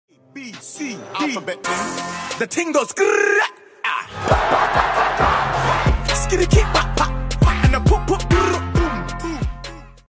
alertboxsound20202.mp3